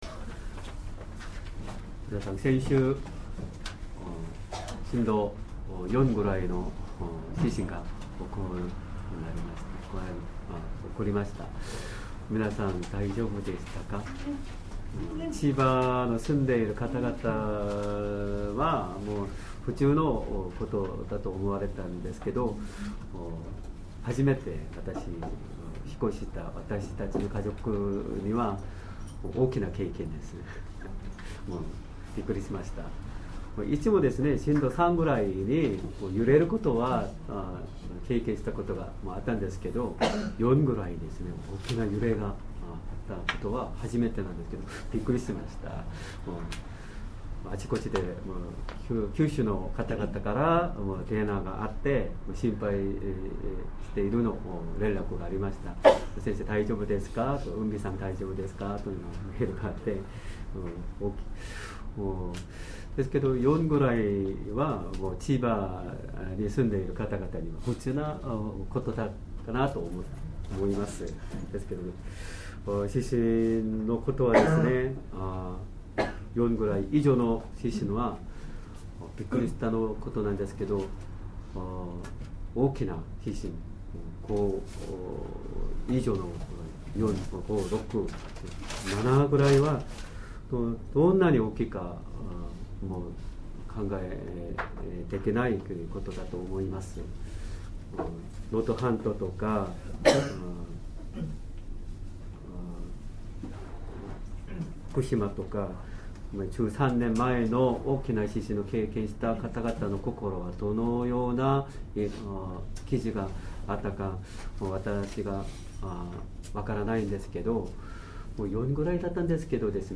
Sermon
2023年 3月24日 主日礼拝 説教 イエス様が十字架につけられた マルコの福音書15章33～47節 15:33 さて、十二時になったとき、闇が全地をおおい、午後三時まで続いた。